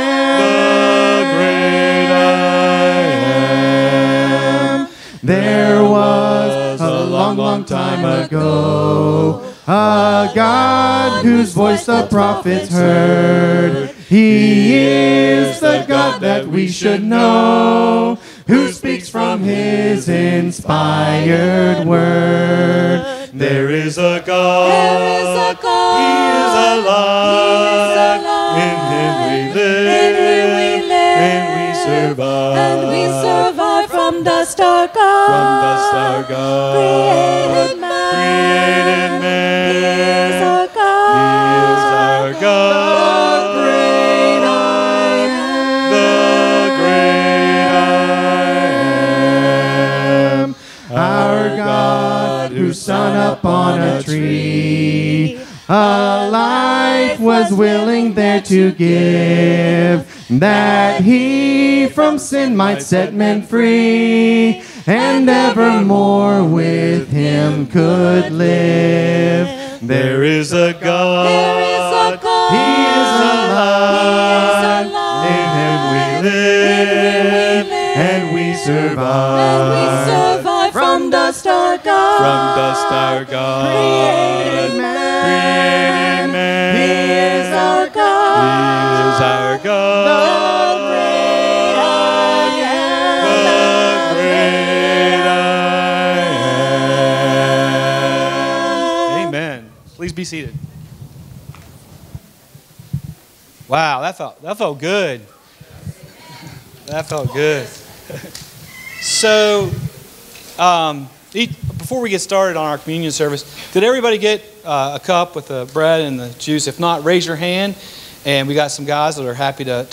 UNITY SERVICE | Kanawha City Church of Christ